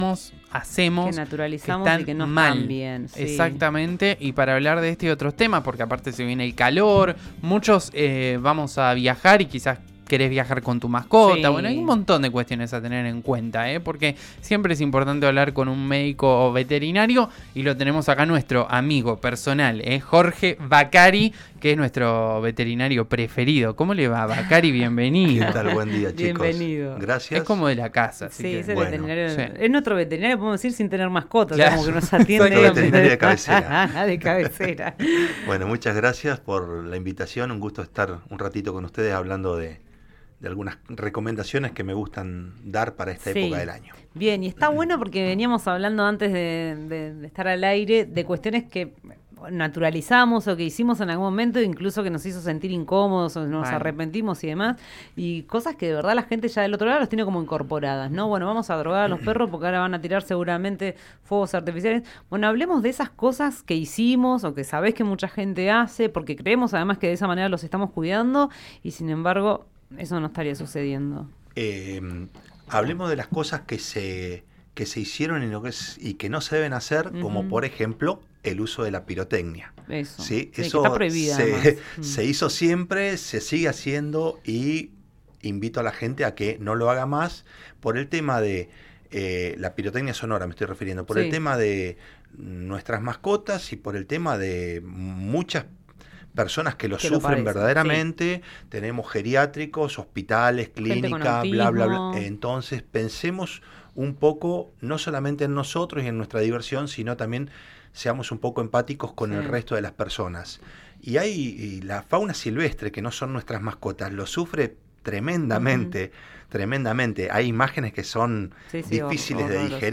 visitó los estudios de «El diario del Mediodía»